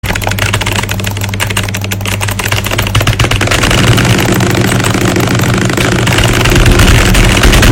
Keyboard rage
keyboard-rage.mp3